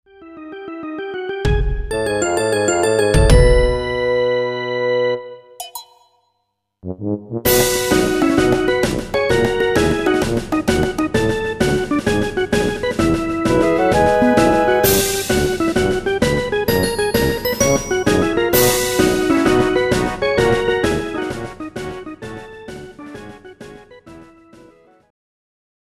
マーチ
マーチを書いてみたりしてます。
マーチって２/４拍子で特徴のあるビートの刻み方をしますけど、作るとなるとどうしてもディズニーっぽくなってしまうのはなぜだろうか・・・^^;
march.jpgスネアがしんどい^^;
リアルタイムで打ち込んだらリズム狂いまくるし・・・ しんどいけど全部手打ち